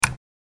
click-button.wav